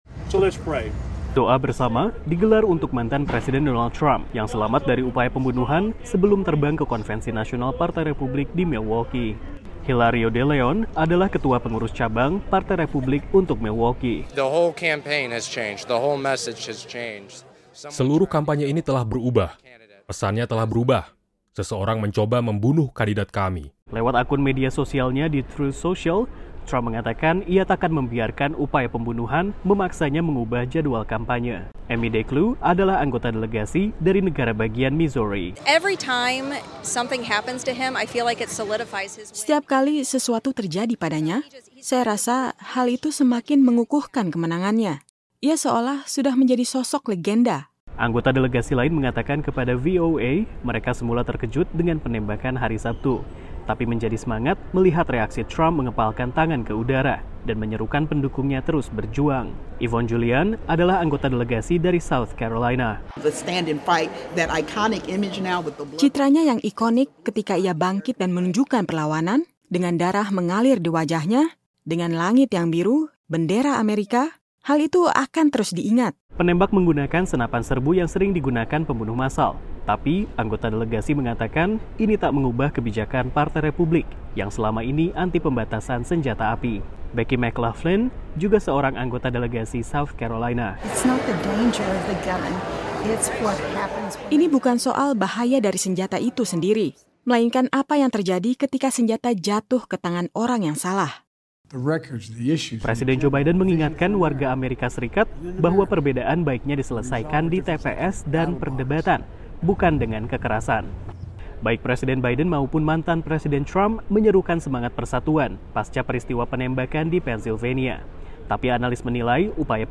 Tim VOA melaporkan dari Milwaukee, Wisconsin.